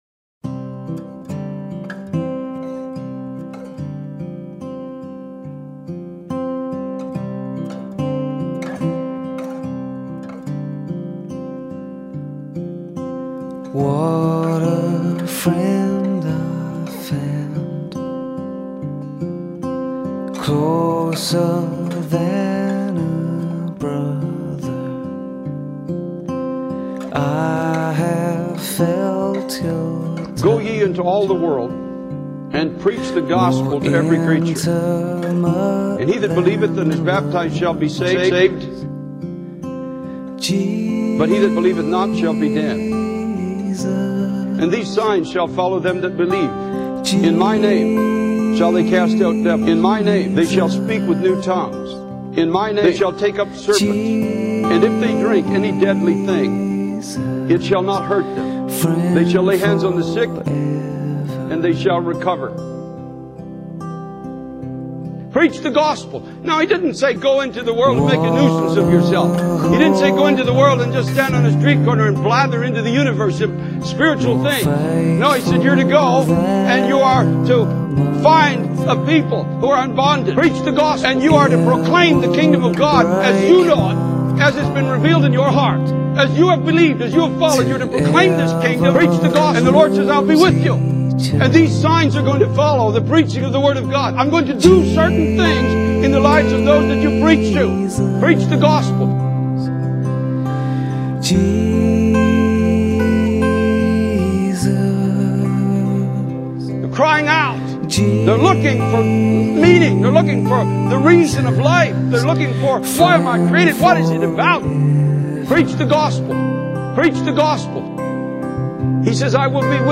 Preach the Gospel (Compilation)
He passionately calls for a commitment to proclaim the Kingdom of God, assuring that God will be with them as they obey this command.